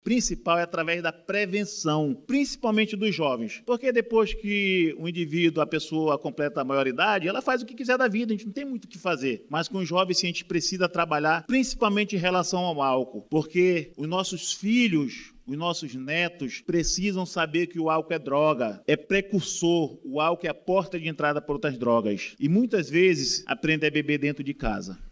A prevenção e o combate às drogas nas escolas municipais de Manaus foram colocados em debate, durante o Grande Expediente da Câmara Municipal de Manaus – CMM, desta terça-feira 24/02.
O parlamentar defendeu que a prevenção voltada ao público jovem é o melhor caminho para o combate às drogas.